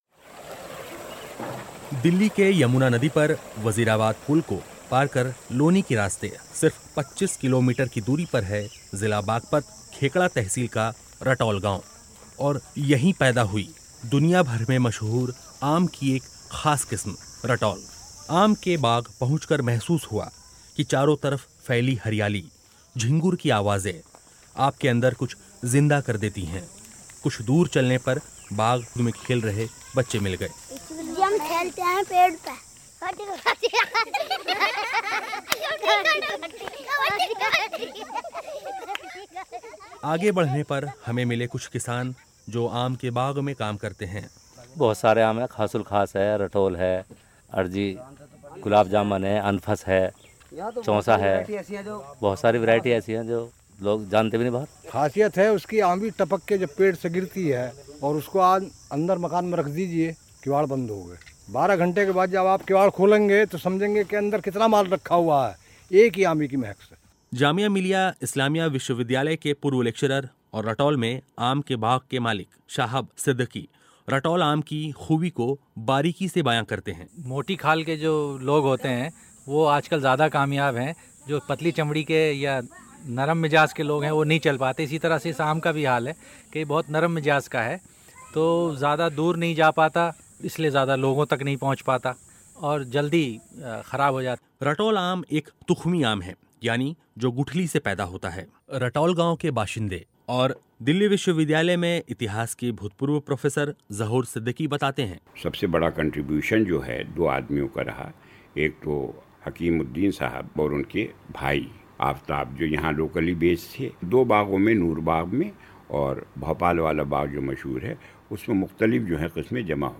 ख़ास रिपोर्ट.